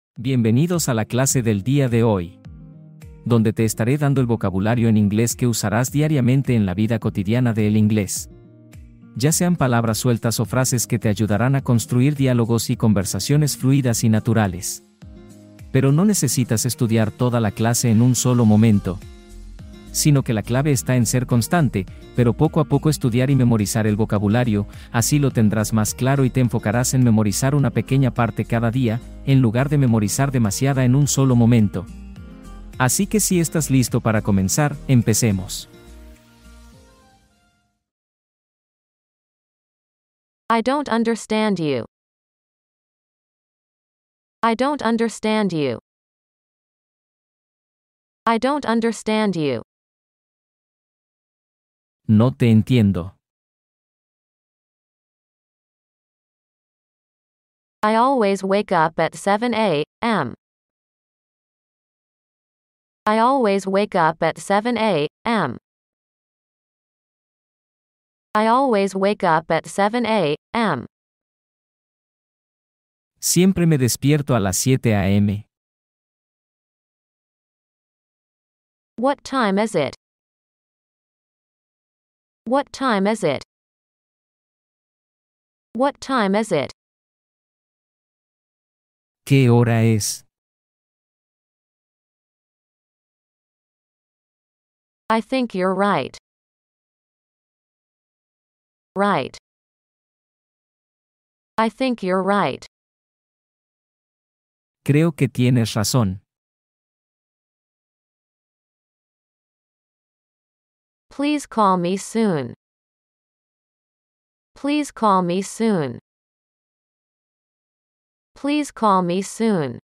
La vida diaria en inglés ☕ | Práctica de listening para entrenar tu oído con conversaciones reales ⛅